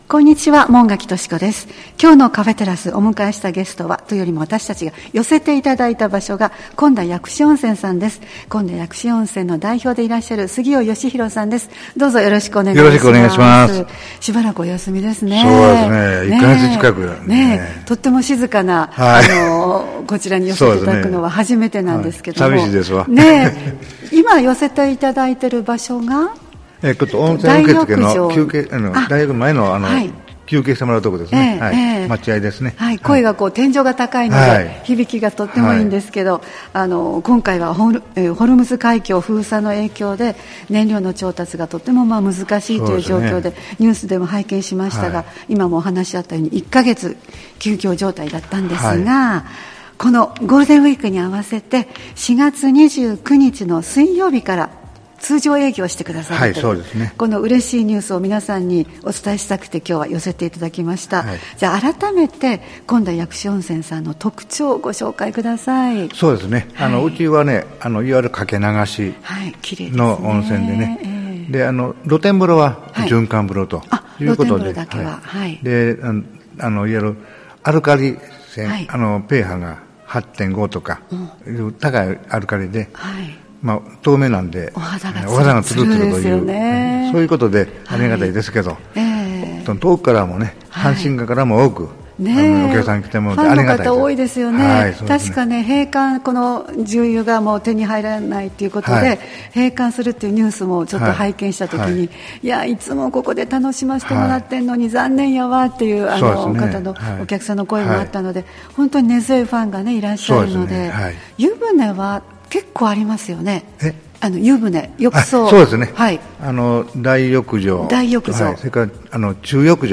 様々な方をスタジオにお迎えするトーク番組「カフェテラス」（再生ボタン▶を押すと放送が始まります）